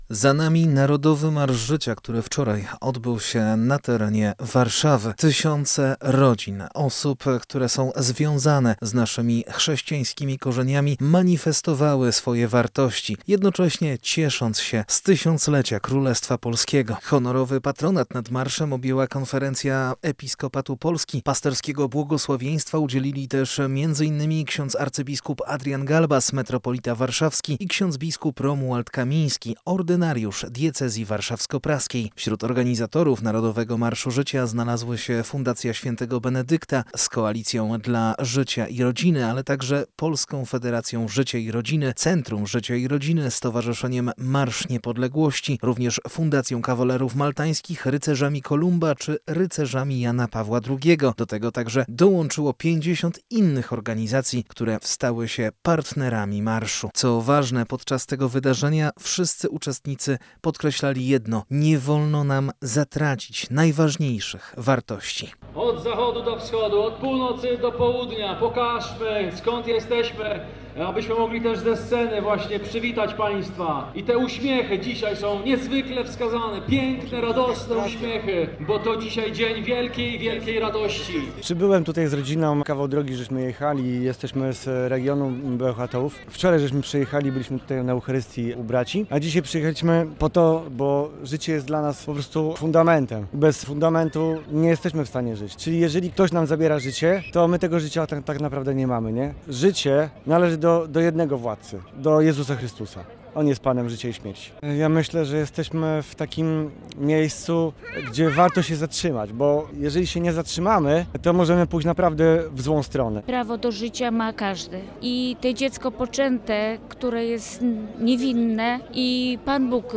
Marsz-Zycia-relacja-.mp3